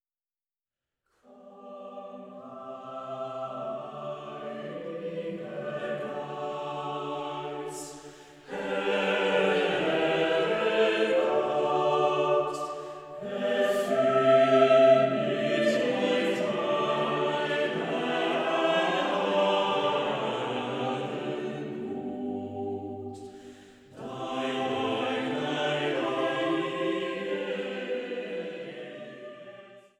Chorwerke auf Gesänge Martin Luthers